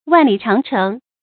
注音：ㄨㄢˋ ㄌㄧˇ ㄔㄤˊ ㄔㄥˊ
萬里長城的讀法